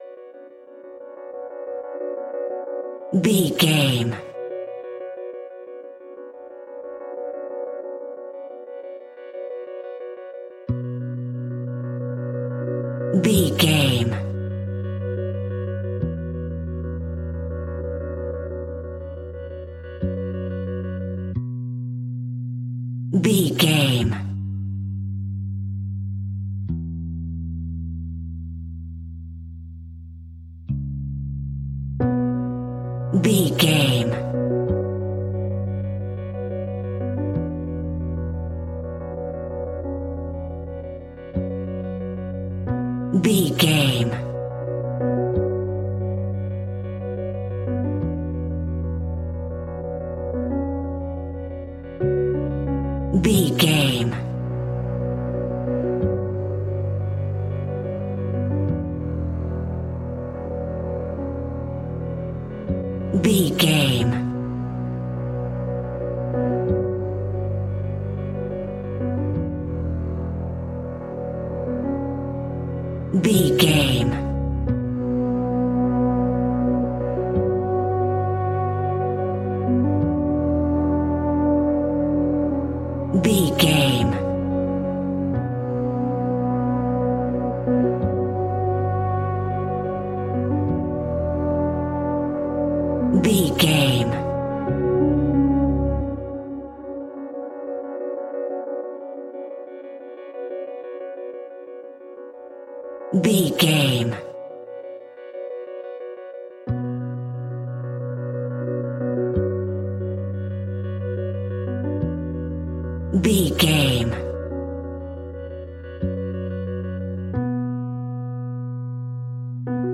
Aeolian/Minor
ominous
dark
haunting
eerie
synthesiser
drums
strings
piano
instrumentals
horror music
Horror Pads
Horror Synths